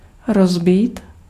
Ääntäminen
IPA: [ˈbryːtˌa]